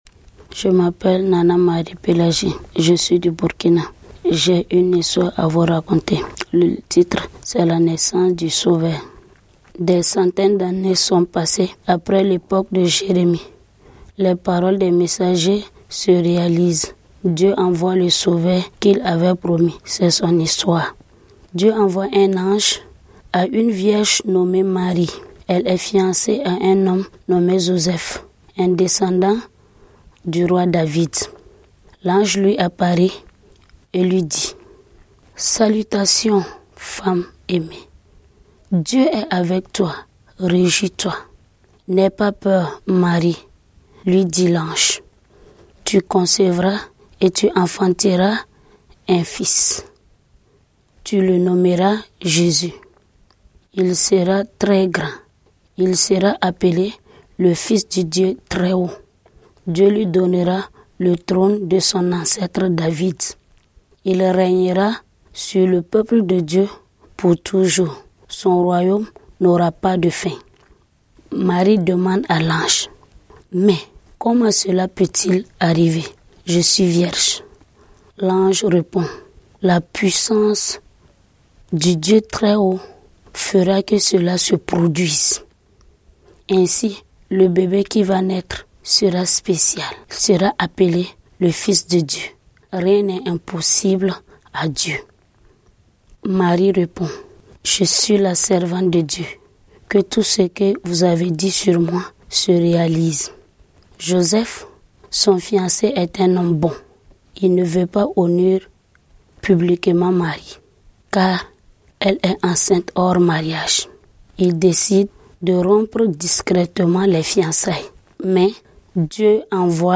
Lisez et écoutez ces histoires bibliques d'hommes riches et d'hommes pauvres et découvrez comment Dieu nous offre la véritable prospérité.